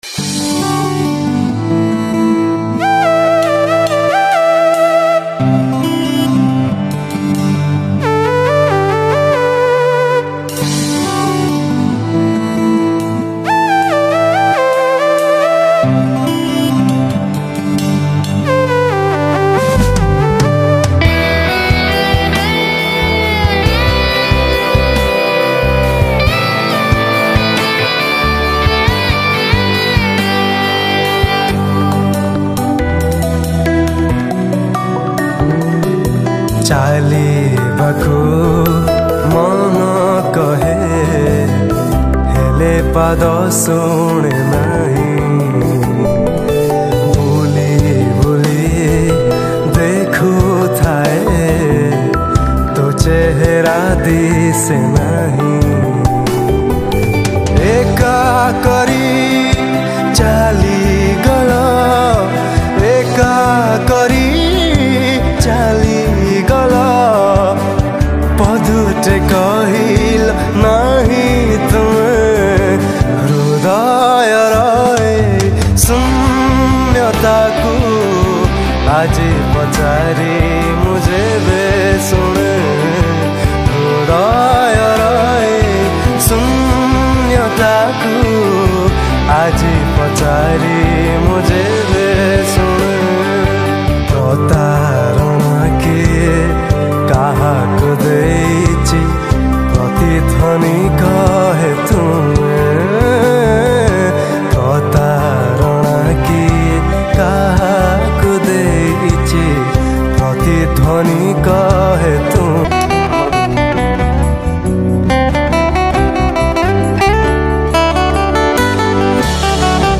Odia Sad Song